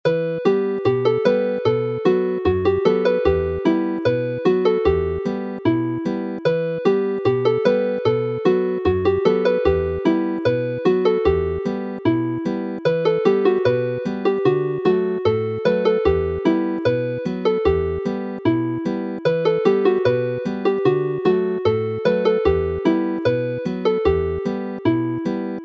Although it is named as a jig, the music is written and played as a polka.